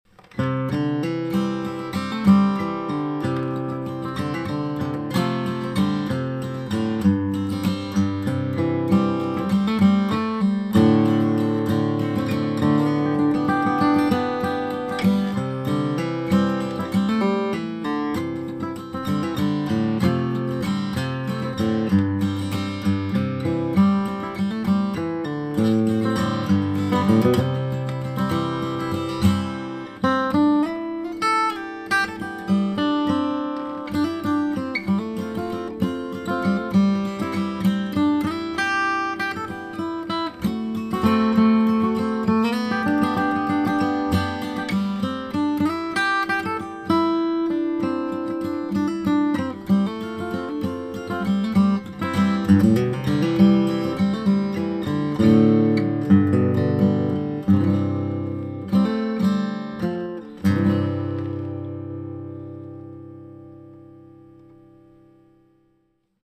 Music Flatpicker Hangout Flatpicker Hangout Members Santa Cruz
Newest 20 Songs flatpicked guitar songs which Flatpicker Hangout members have uploaded to the website.